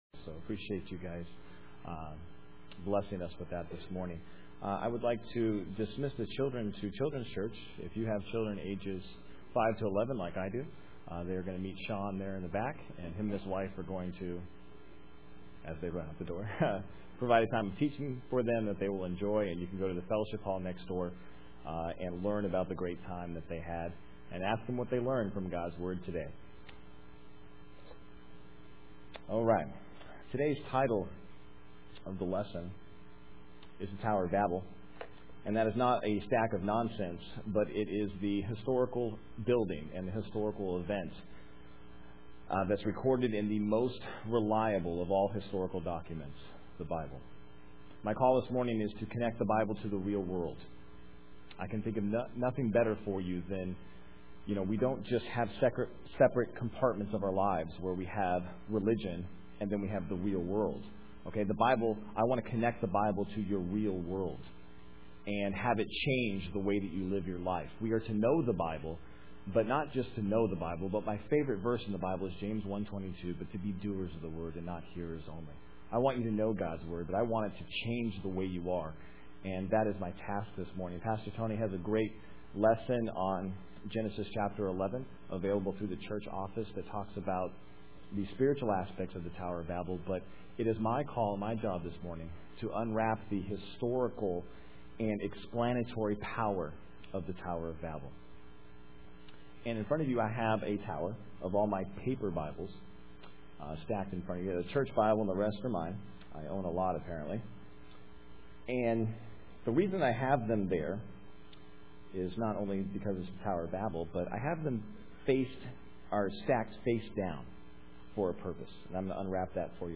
Main Service am